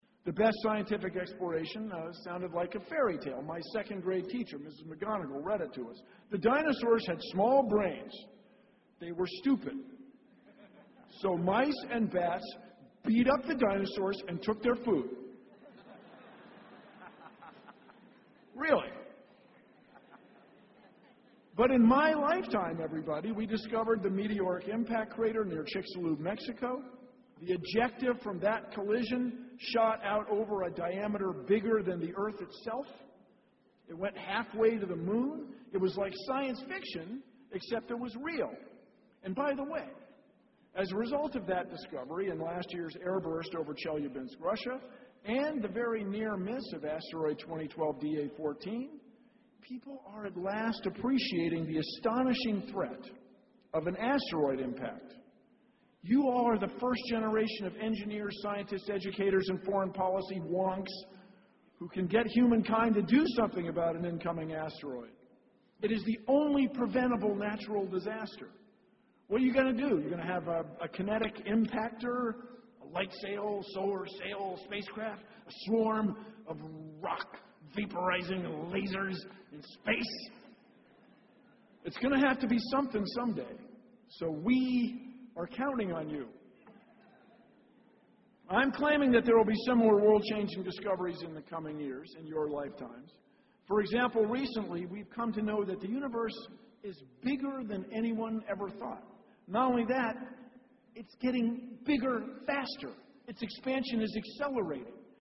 公众人物毕业演讲 第166期:比尔·奈马萨诸塞大学2014(13) 听力文件下载—在线英语听力室